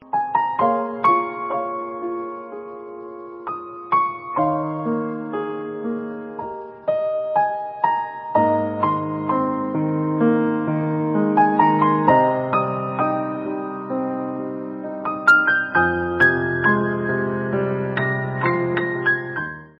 Klasik